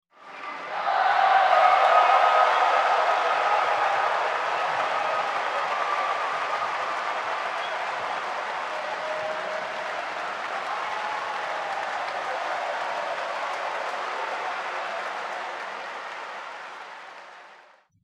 Crowd Ambience
crowd_ambience.mp3